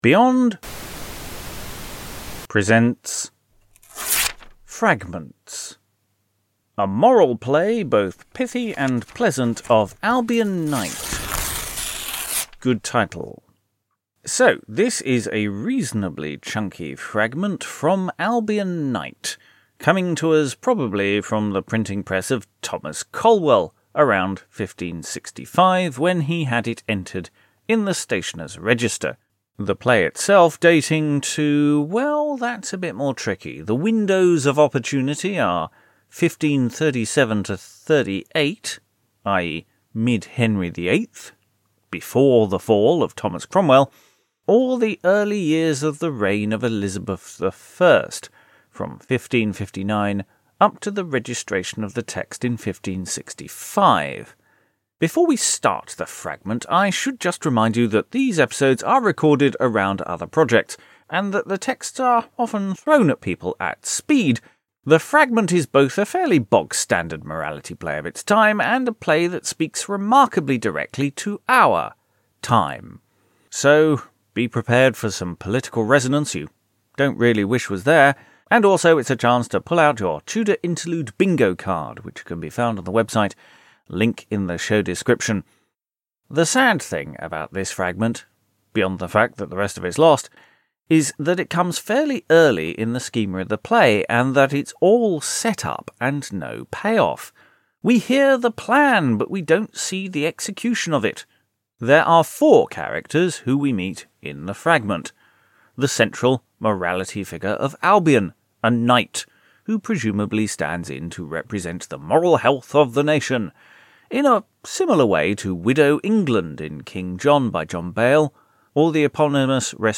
Albion Knight - Full Cast Audio (Fragment)